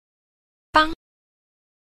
a. 幫 – bāng – bang